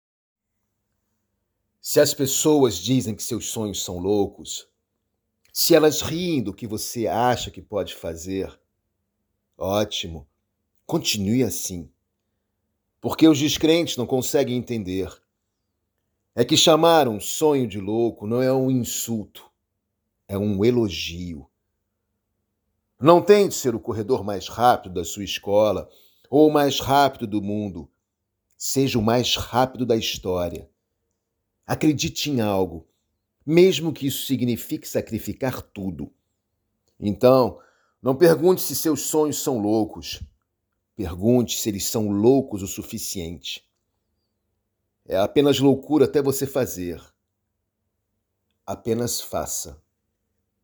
Voix off / Extrait en portugais du Brésil
- Baryton